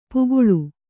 ♪ puguḷu